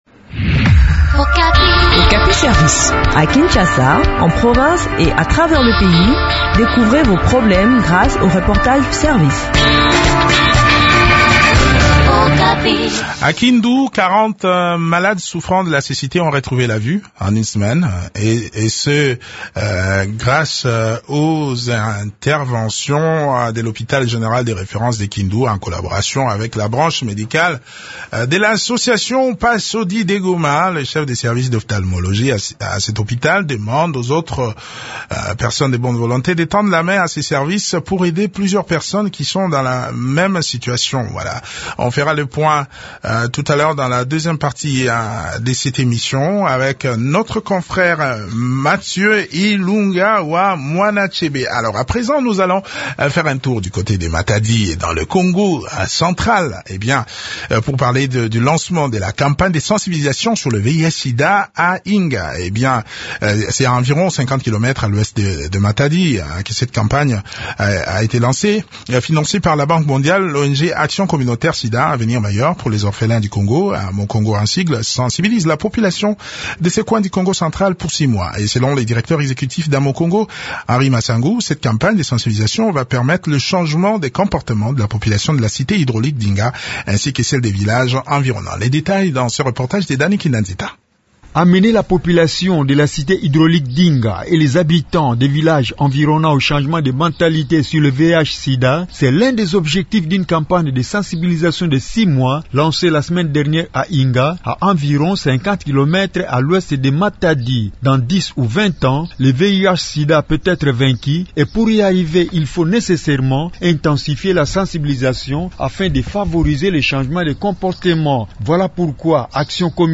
Le point sur le déroulement de cette campagne de sensibilisation dans cet entretien